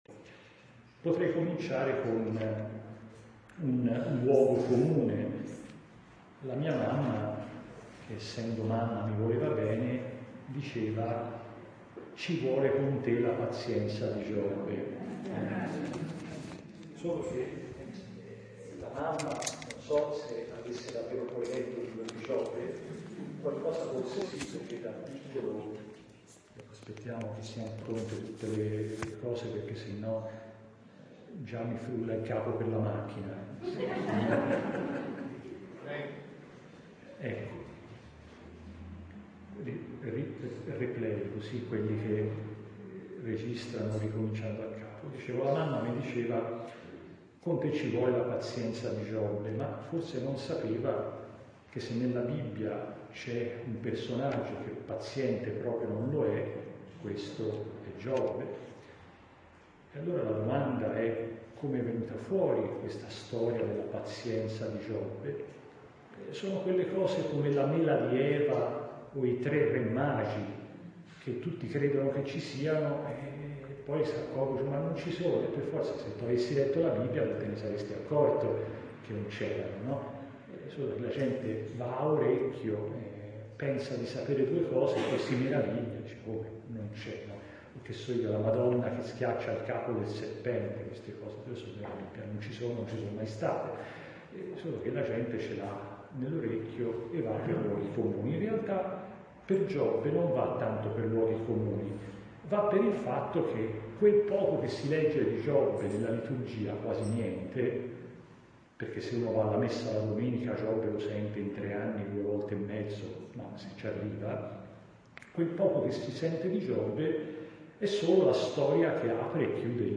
Capire l’oggi meditando con Giobbe. Corso di formazione per catechisti a Maliseti e Vaiano | DIOCESI di PRATO